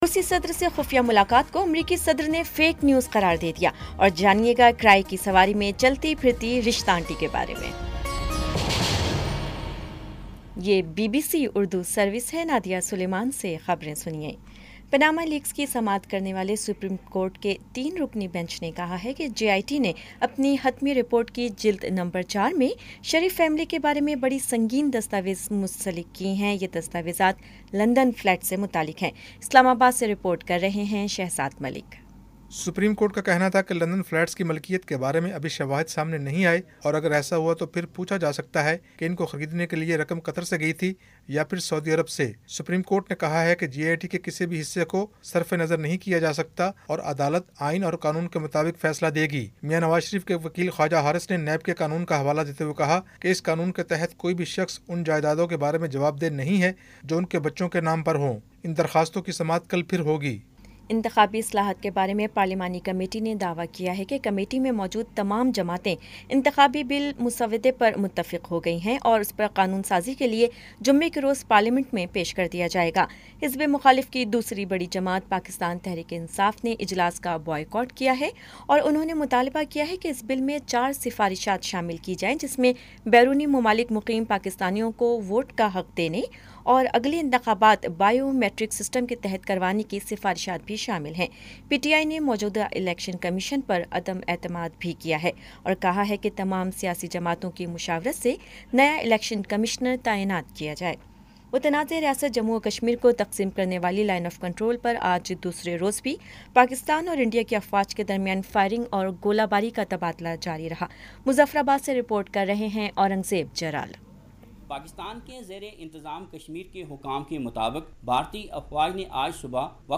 جولائی 19 : شام چھ بجے کا نیوز بُلیٹن